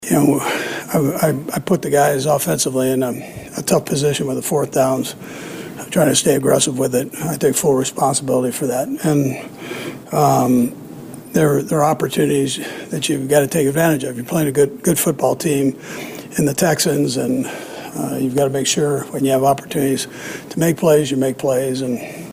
The Chiefs went for it on fourth down a couple of times late in the game and failed on both. Coach Andy Reid talked about it